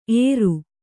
♪ ēru